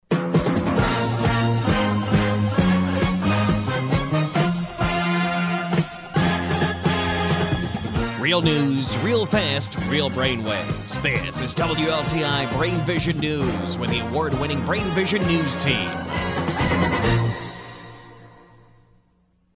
WLTInews.mp3